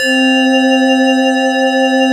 BELDANDY C3.wav